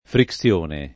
Frixione